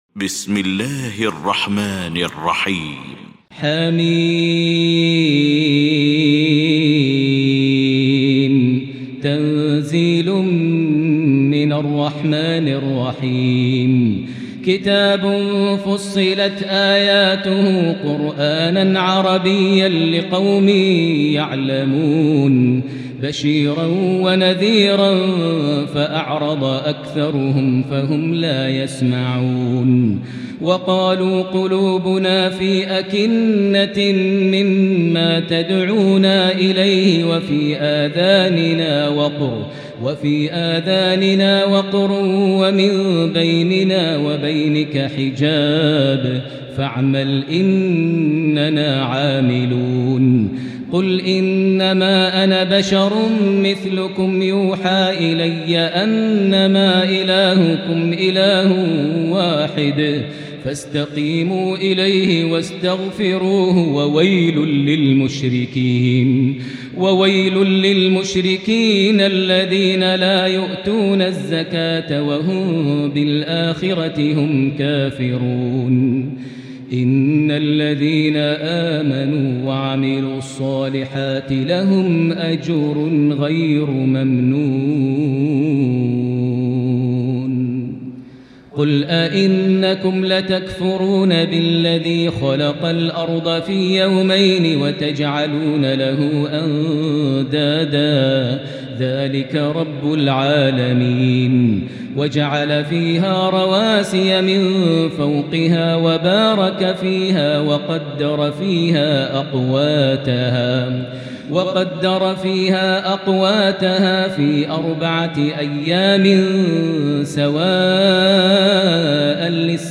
المكان: المسجد الحرام الشيخ: فضيلة الشيخ ماهر المعيقلي فضيلة الشيخ ماهر المعيقلي فضيلة الشيخ ياسر الدوسري فصلت